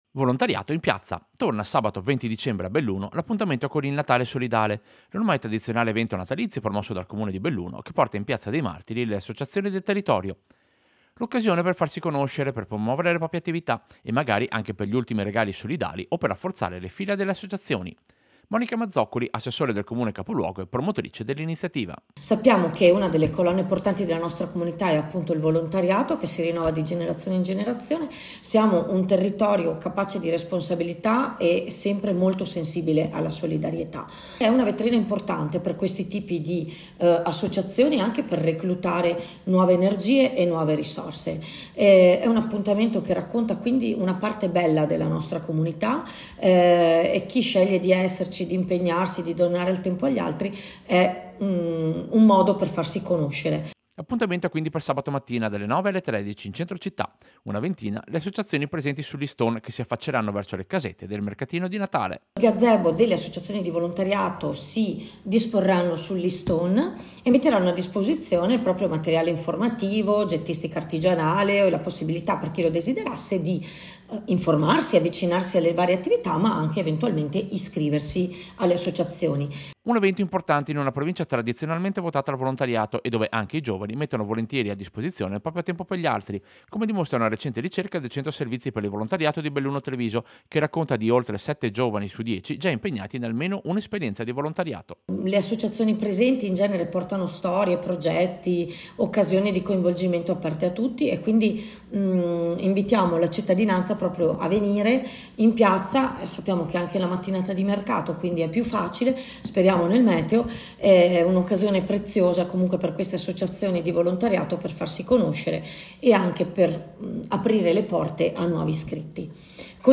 Servizio-Natale-Solidale-2025-Belluno.mp3